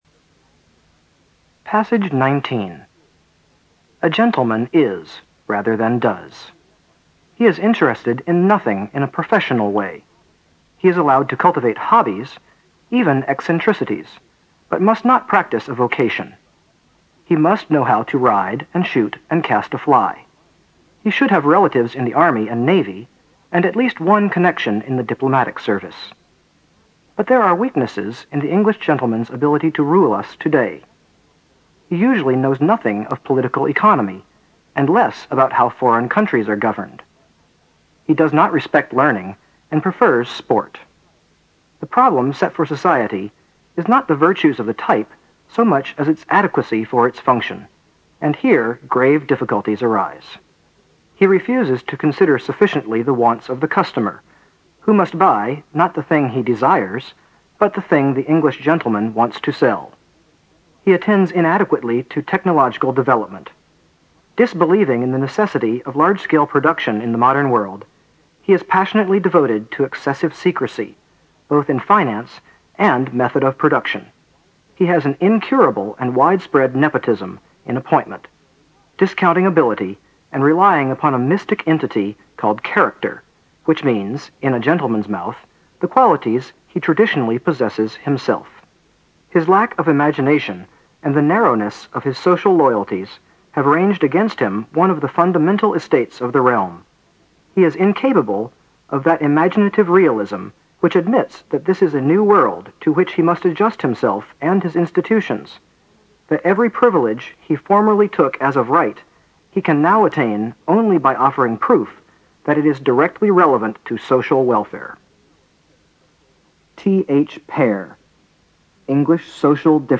新概念英语85年上外美音版第四册 第19课 听力文件下载—在线英语听力室